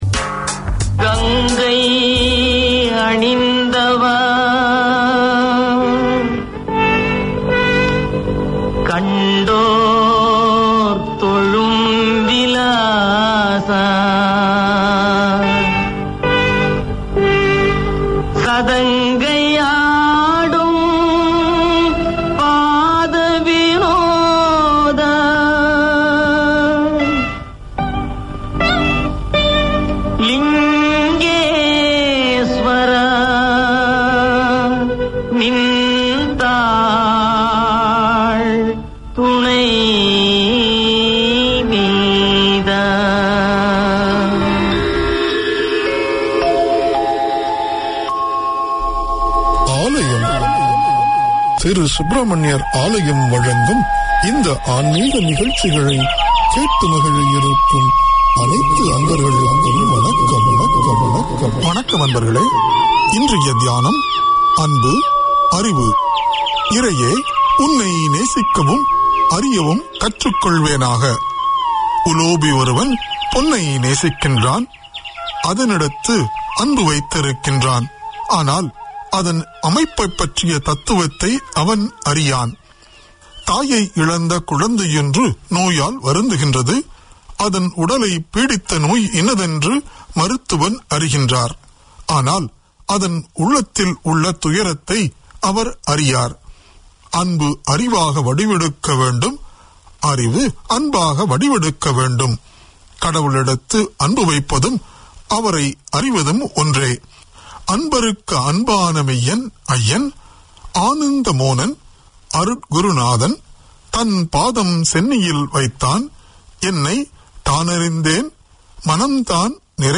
Religious discourses, music, interviews and chats on Hindu religion and culture feature in this 30 minute weekly programme from the NZ Hindu Temple Society. Alayam promotes dualism and understanding of the Vedas, provides Q & A on Vethantham, interviews with Sadhus from overseas and Vinayagar, Murugan, Siva, Vishnu and Amman songs are aired regularly.